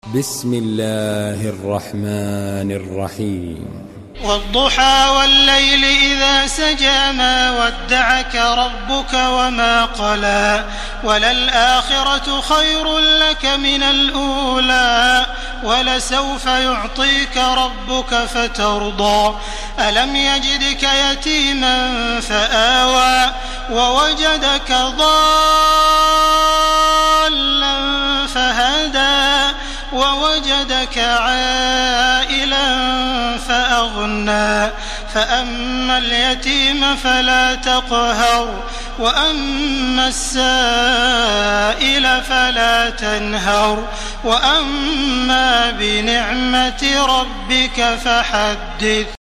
تراويح الحرم المكي 1431
مرتل حفص عن عاصم